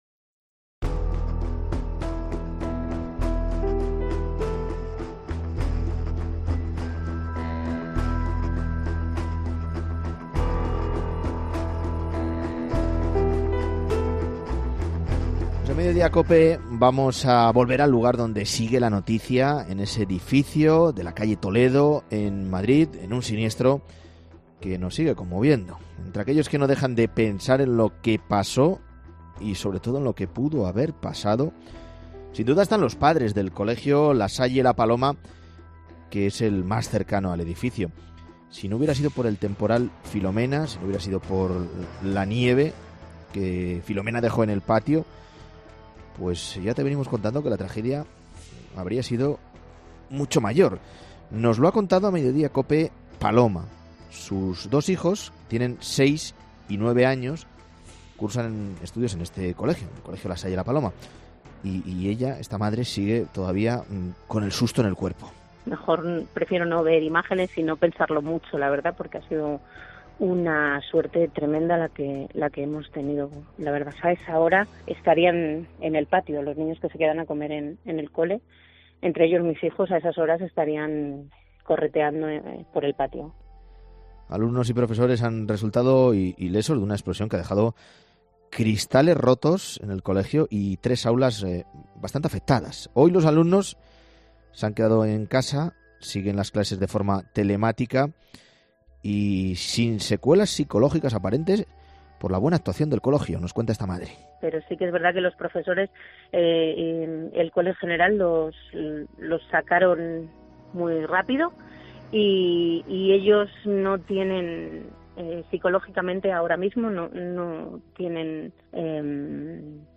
Testigos directos de la explosión siguen recuperándose del susto